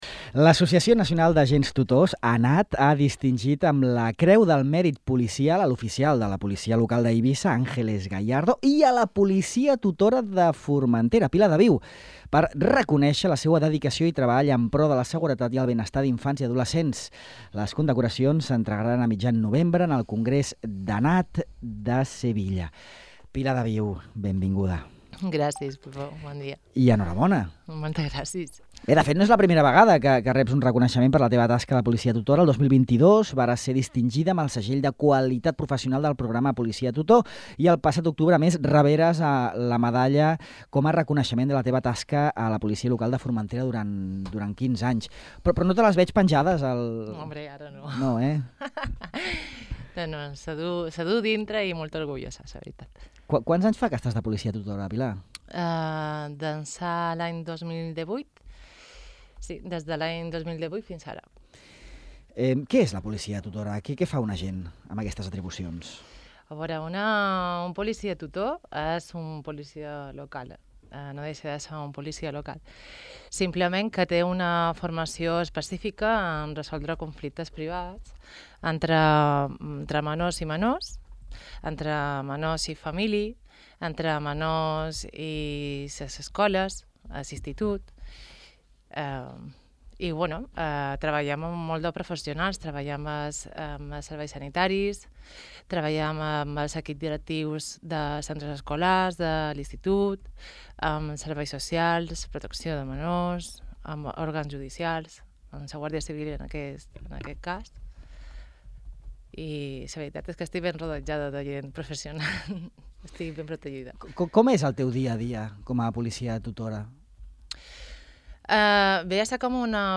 En aquesta entrevista a Ràdio Illa, l’agent remarca que la seva tasca amb els menors es concentra en prevenir i intervenir en casos d’assetjament, ciberassetjament i consum de substàncies tòxiques.